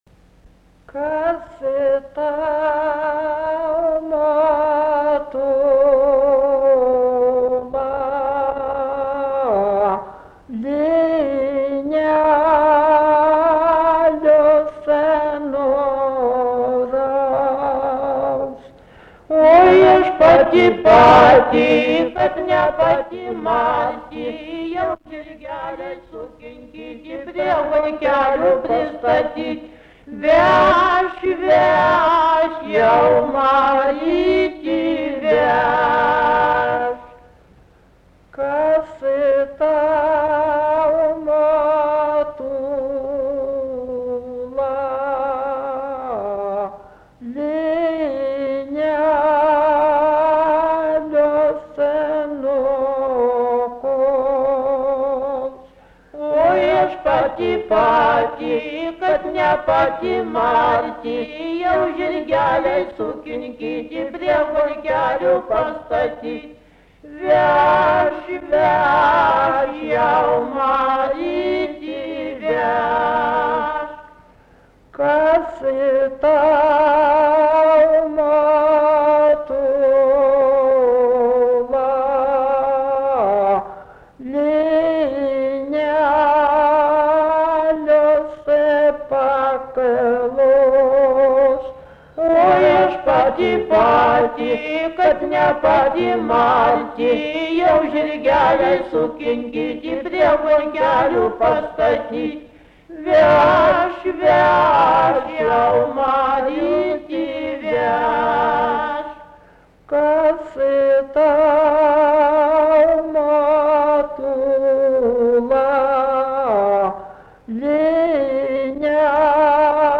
Subject daina
Atlikimo pubūdis vokalinis